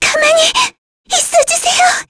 Lavril-Vox_Skill3_kr.wav